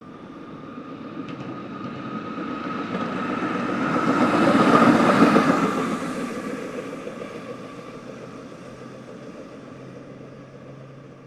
Melbourne Tram By